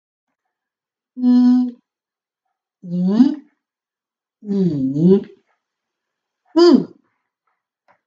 Hieronder hoor je hoe de verschillende tonen moeten worden uitgesproken.
ī í ǐ ì (audio)
16-i-i-i-i-_audio.mp3